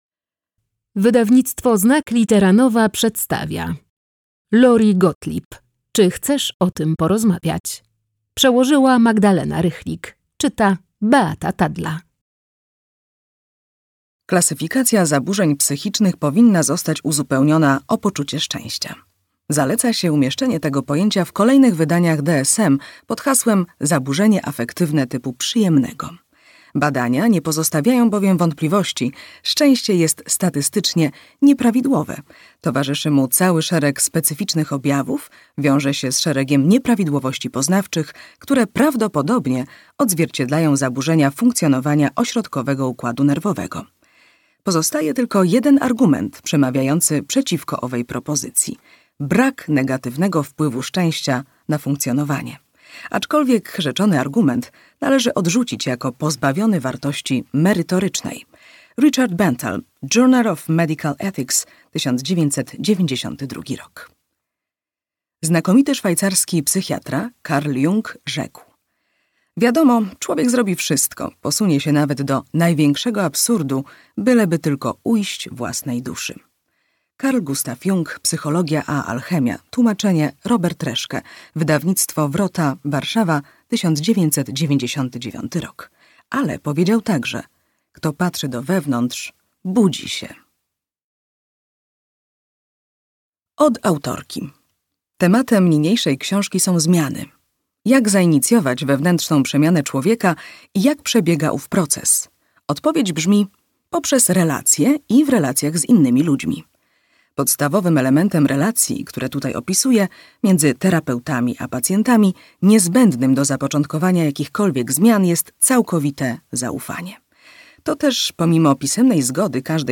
Czy chcesz o tym porozmawiać - Gottlieb Lori - audiobook